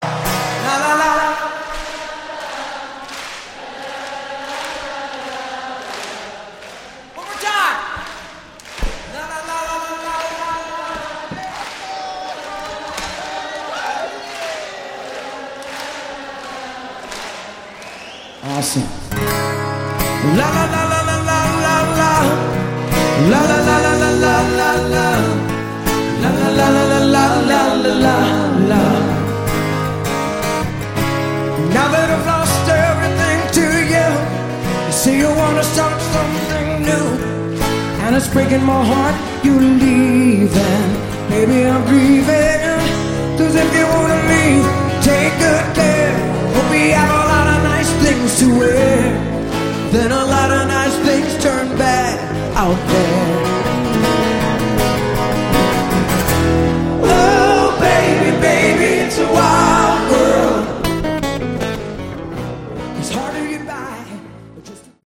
Category: Hard Rock
Bass
vocals
Drums
Guitars